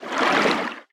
Sfx_creature_titanholefish_swim_02.ogg